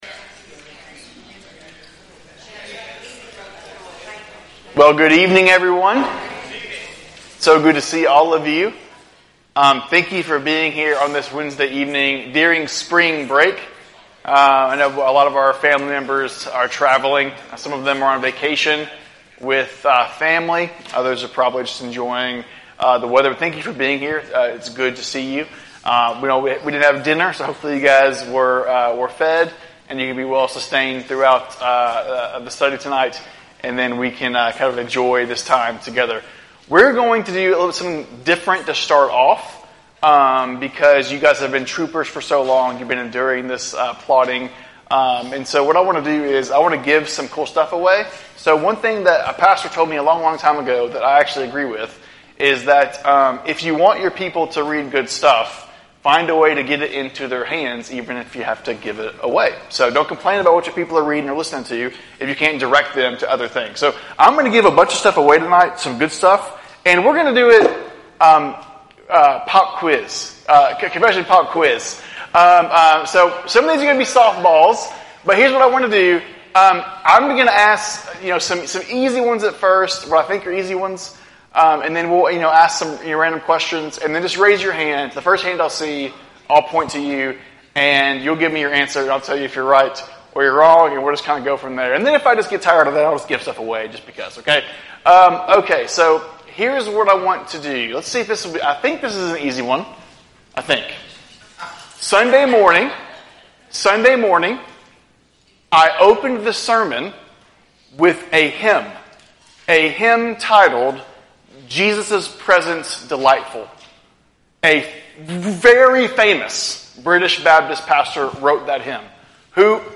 A message from the series "Mid-Week Bible Studies."